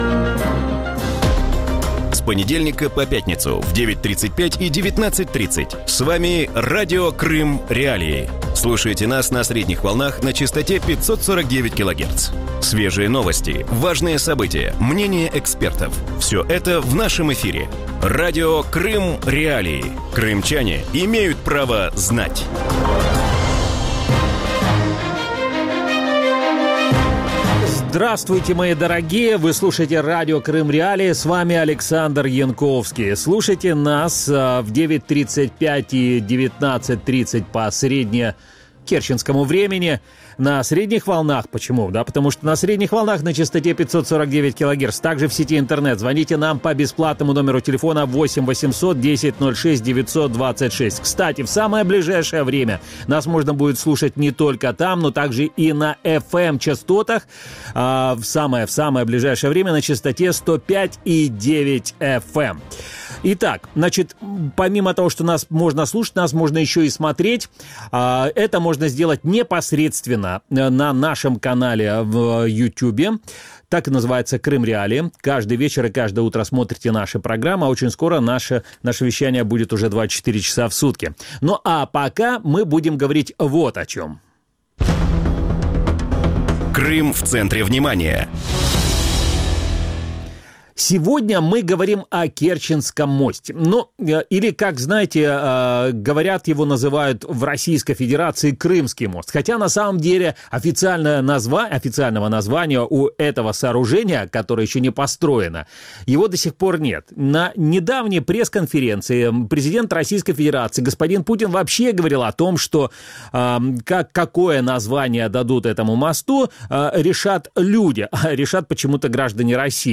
У вечірньому ефірі Радіо Крим.Реалії обговорюють, чому в Росії не можуть знайти генерального підрядника на будівництво залізниці до Криму. Чому російські компанії відмовляються прокладати залізничну гілку через міст, що будується і на скільки може затягнутися будівництво?